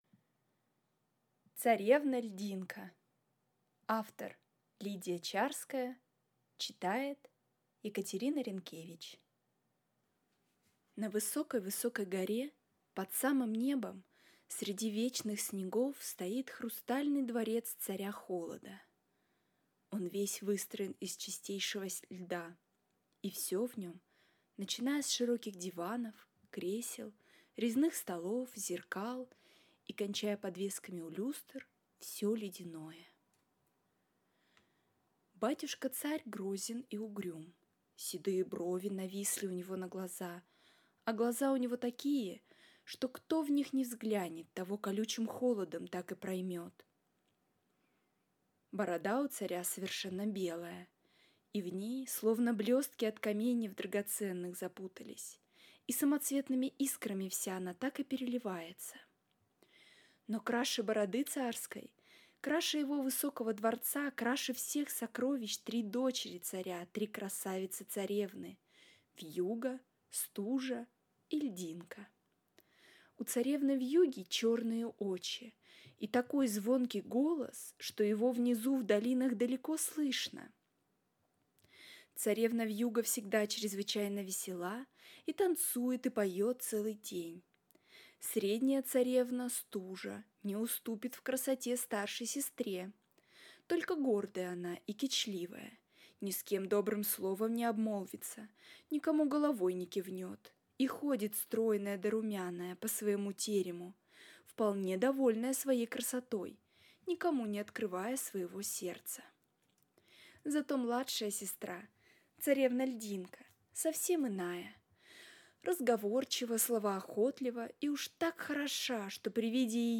Аудиокнига Царевна Льдинка | Библиотека аудиокниг